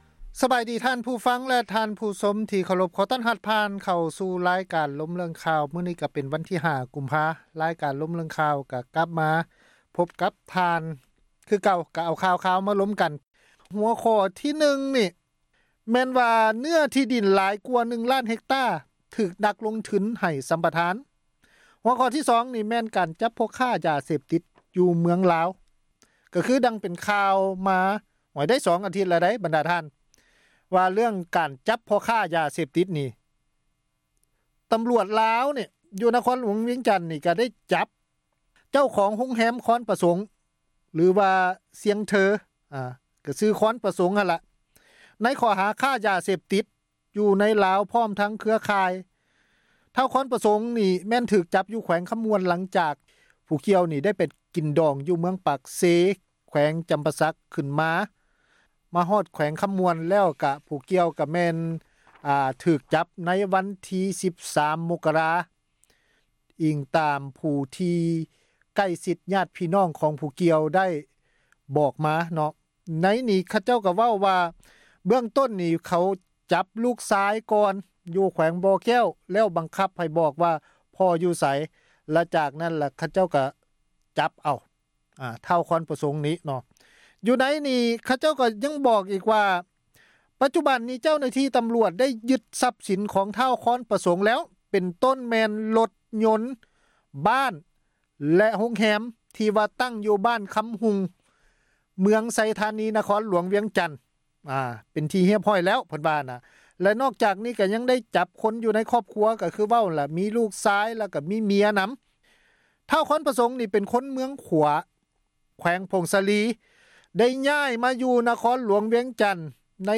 ການສົນທະນາ ເຖິງເຫດການ ທີ່ເກີດຂຶ້ນ ໃນແຕ່ລະມື້ ທີ່ມີຜົນກະທົບ ຕໍ່ຊີວິດປະຈໍາວັນ ຂອງຊາວລາວ ທົ່ວປະເທດ ທີ່ ປະຊາສັງຄົມ ເຫັນວ່າ ຂາດຄວາມເປັນທັມ.